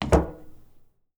footstep SFX
footsteps / metal
metal6.wav